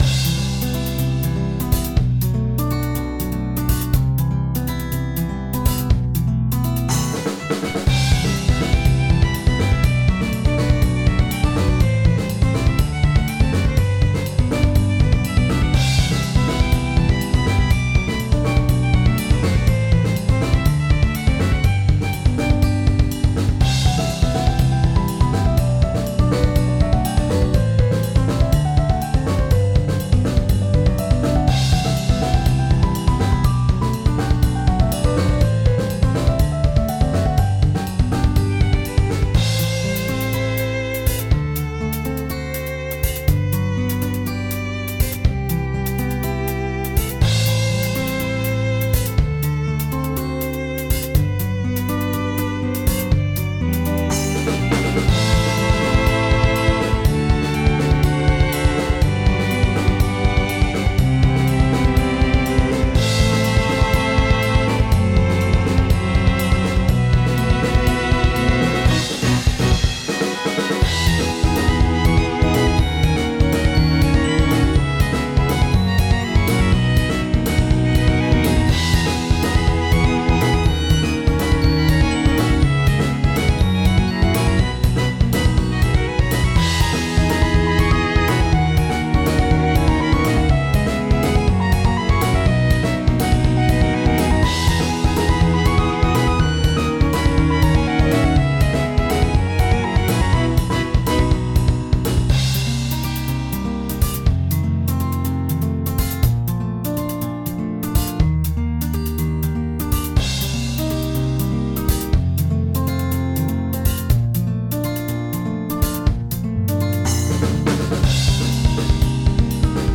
フリーBGM素材- 草原とか森の浅い場所で、素材蒐集！ってかんじの曲。